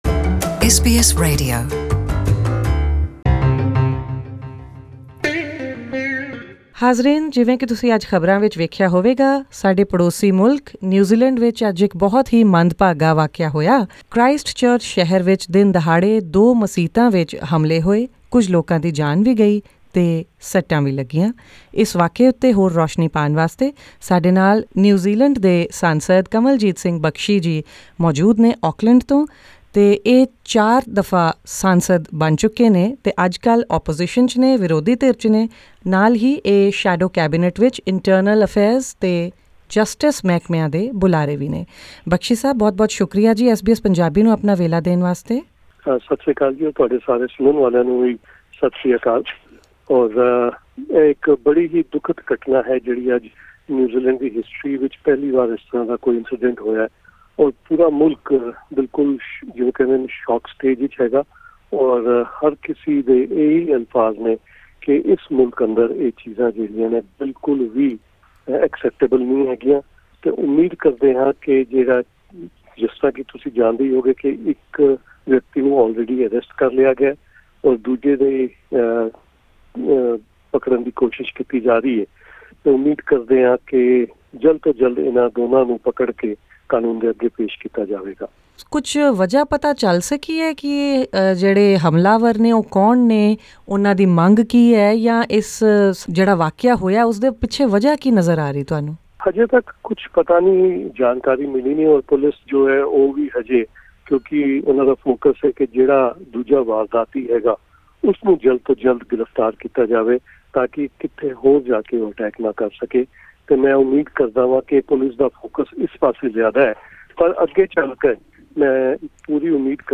Kanwaljit Singh Bakshi, MP speaks to SBS Punjabi from Auckland.